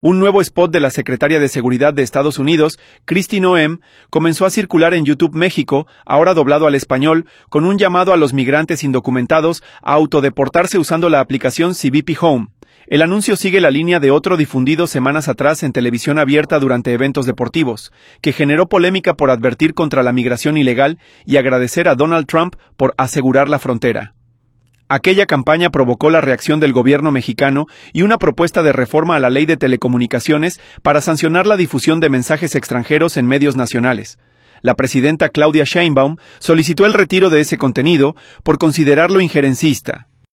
audio Un nuevo spot de la secretaria de Seguridad de Estados Unidos, Kristi Noem, comenzó a circular en YouTube México, ahora doblado al español, con un llamado a los migrantes indocumentados a autodeportarse usando la app CBP HOME.